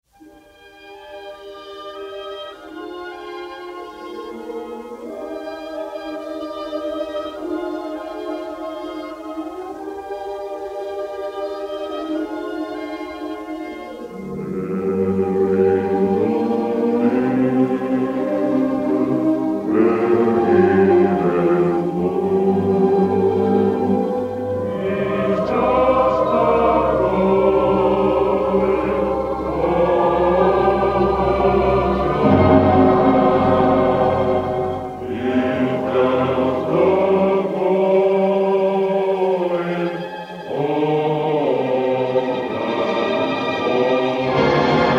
a gorgeous score brimming with sympathy and melancholy.
The sound quality is largely "archival,"